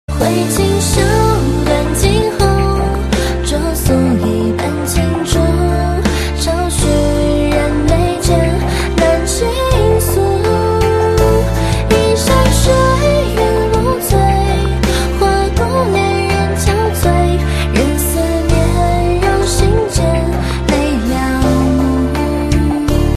M4R铃声, MP3铃声, 华语歌曲 36 首发日期：2018-05-14 10:52 星期一